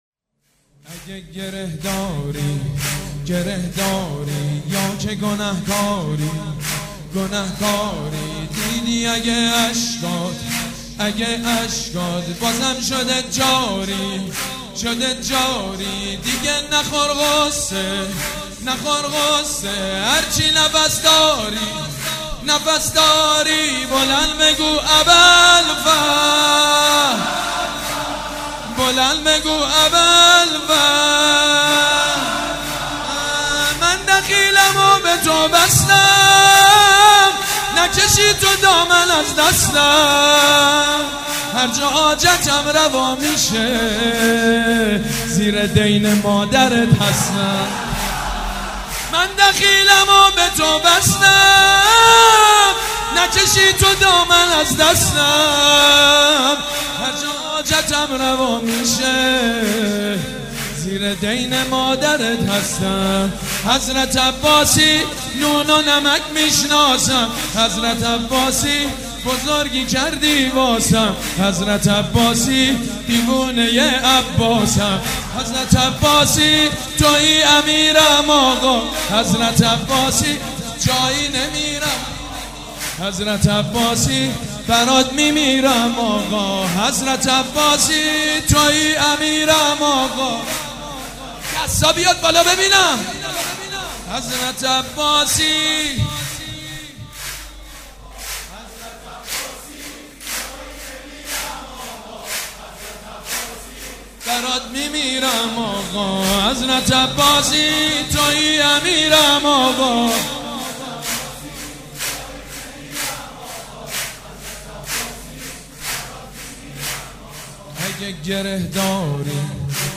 سرود: اگه گره داری، گره داری